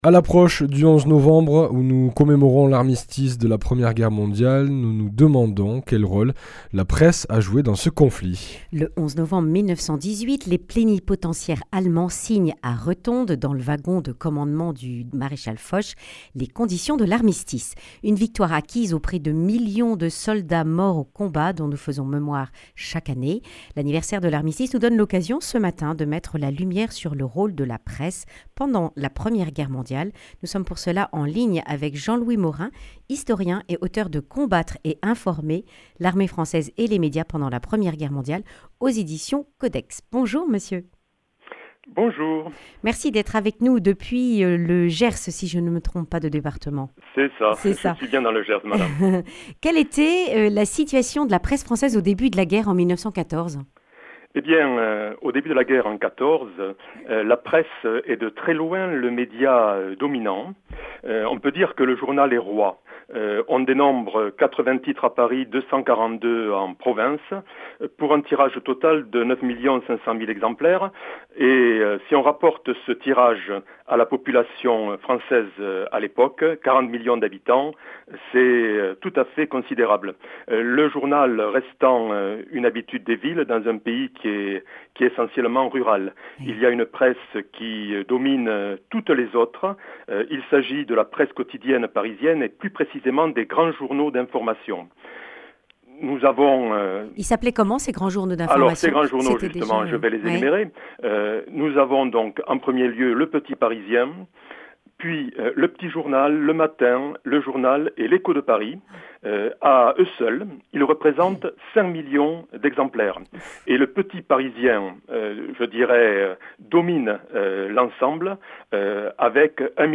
Accueil \ Emissions \ Information \ Régionale \ Le grand entretien \ Quel rôle a joué la presse pendant la première guerre mondiale ?